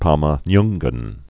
(pämə-nynggən)